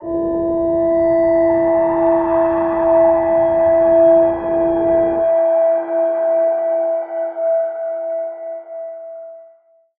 G_Crystal-E5-pp.wav